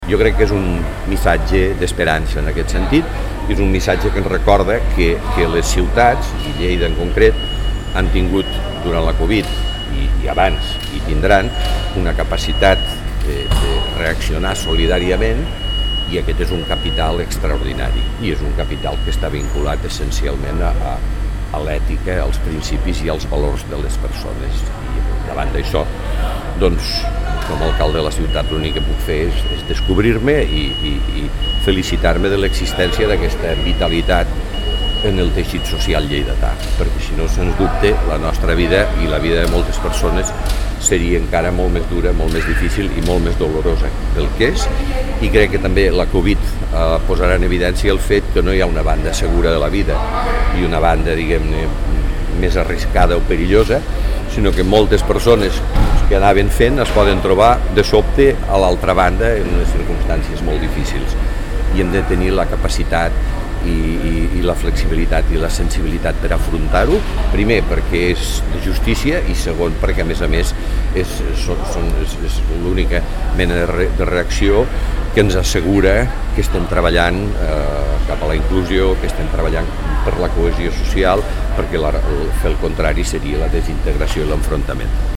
Fitxers relacionats Tall de veu de l'alcalde, Miquel Pueyo